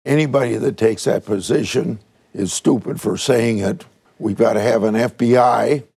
Grassley made his comments during taping of the “Iowa Press” program that airs tonight on Iowa PBS.